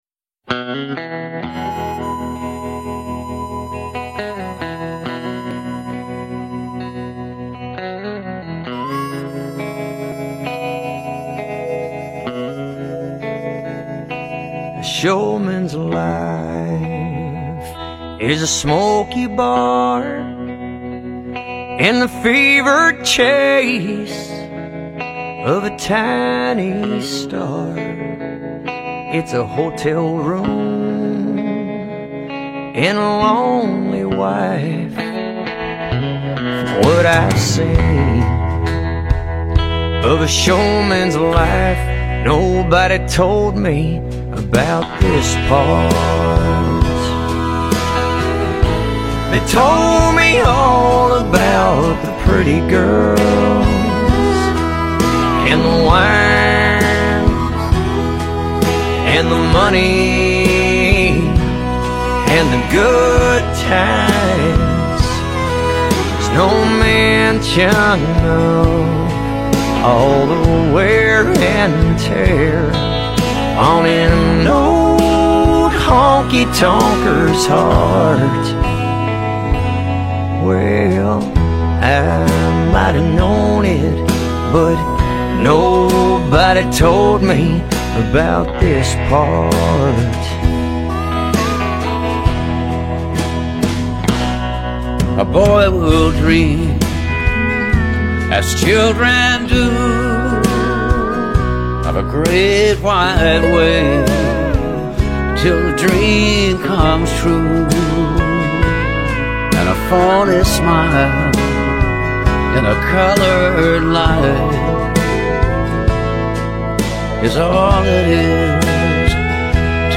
soft USA country